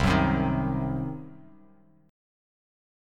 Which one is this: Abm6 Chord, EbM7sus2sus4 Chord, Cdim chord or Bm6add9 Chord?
Cdim chord